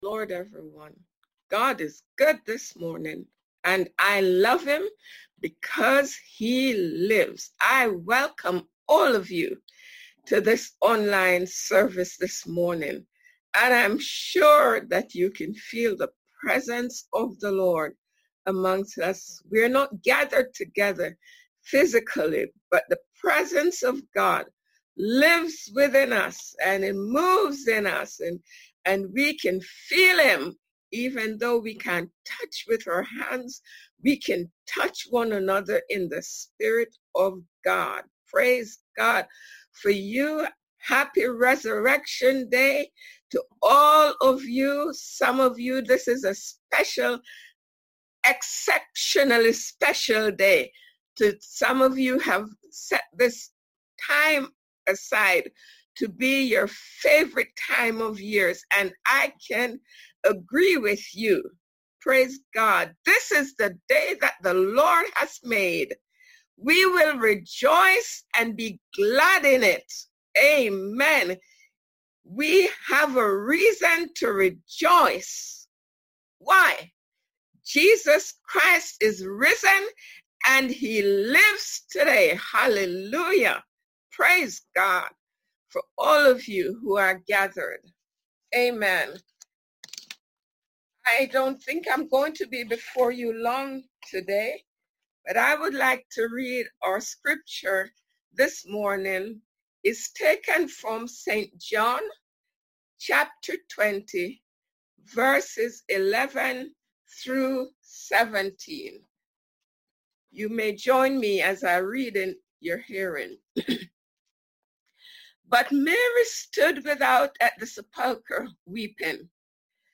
Resurrection Sunday message